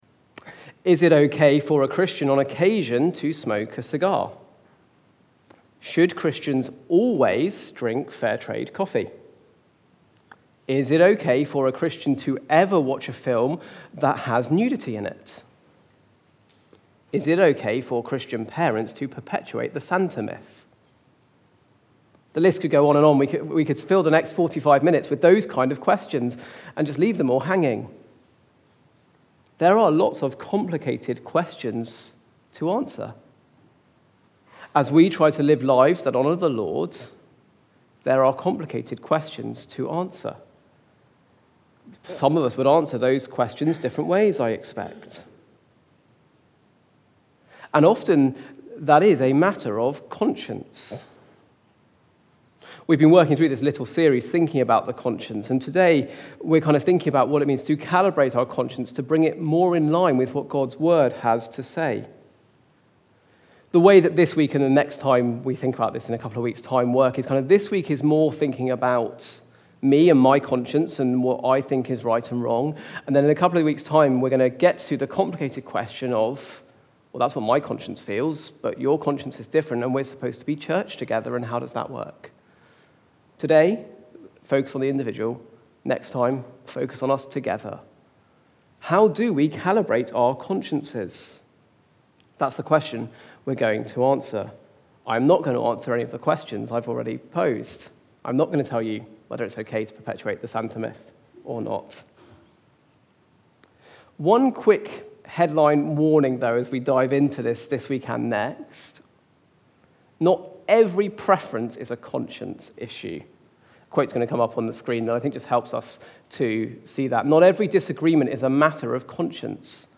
That Calls for Careful Calibration from the series That Little Voice. Recorded at Woodstock Road Baptist Church on 08 October 2023.